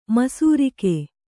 ♪ masūrike